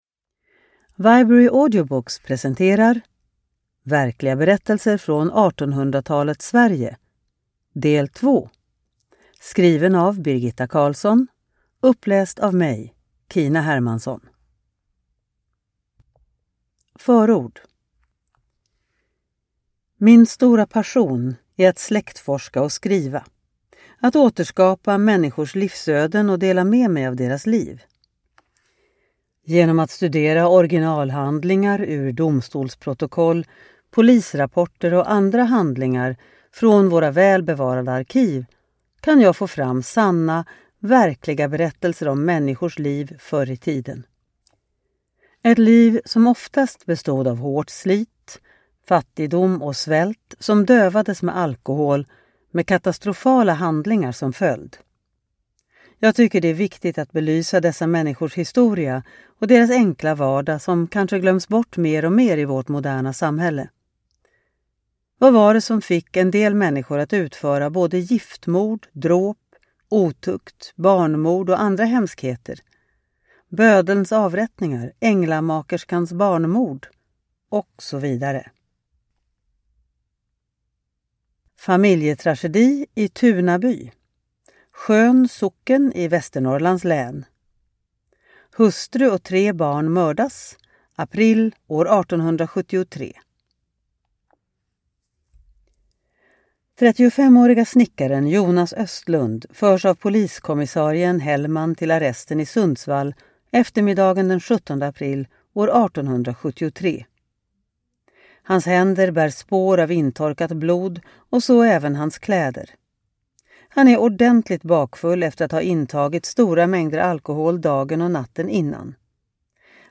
Verkliga berättelser från 1800-talets Sverige: Del 2 (ljudbok) av Birgitta Karlsson